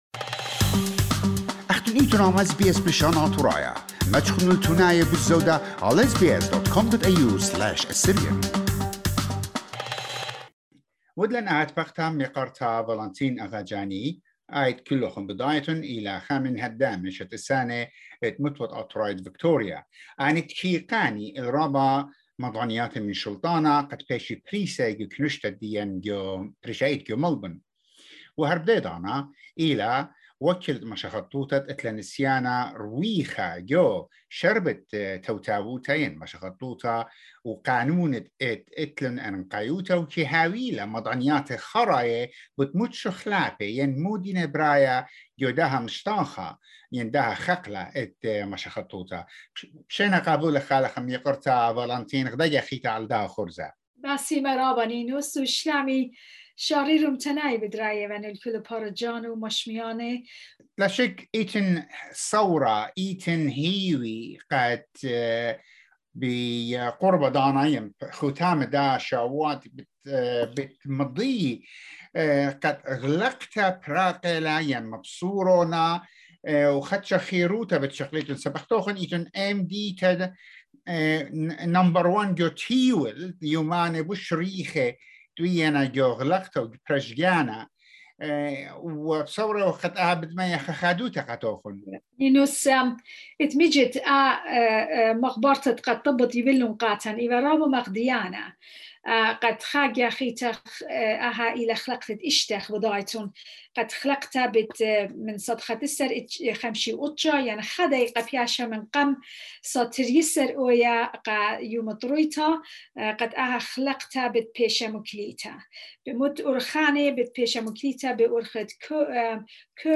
In this interview, she is taking about the Victorian government instructions and messages to the Assyrian community in Melbourne.